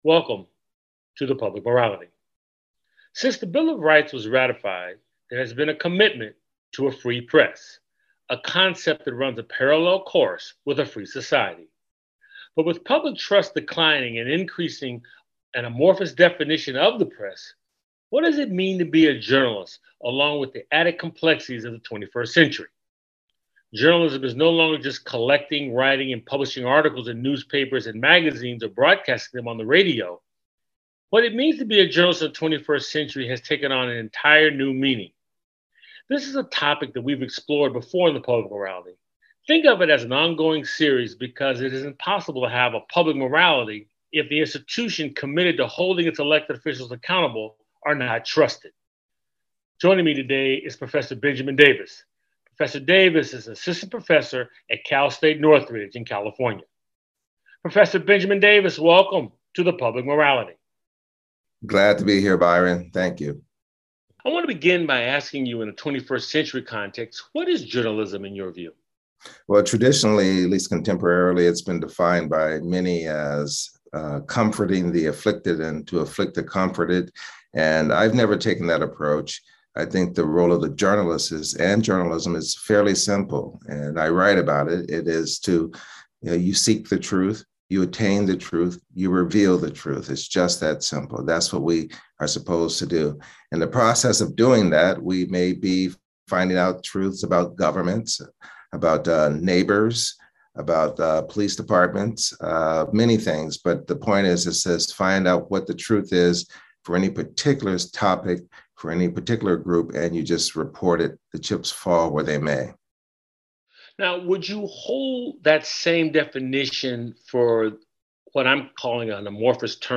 The show airs on 90.5FM WSNC and through our Website streaming Tuesdays at 7:00p.